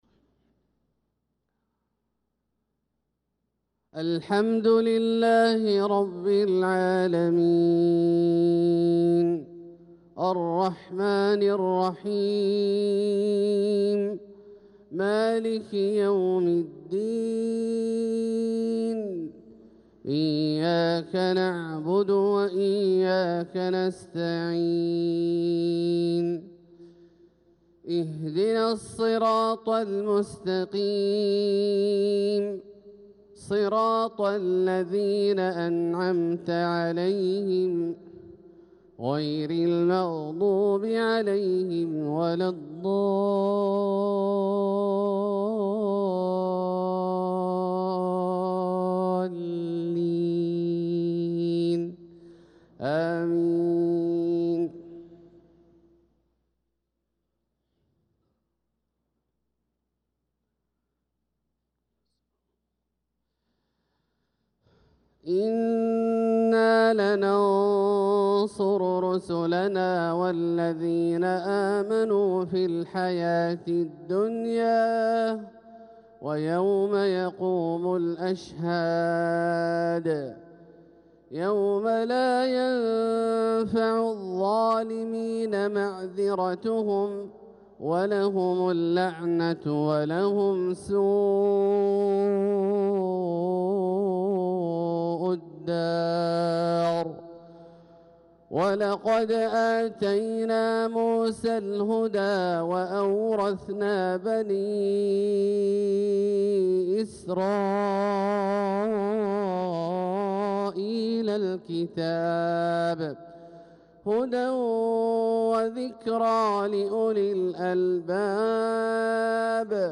صلاة الفجر للقارئ عبدالله الجهني 24 ذو الحجة 1445 هـ
تِلَاوَات الْحَرَمَيْن .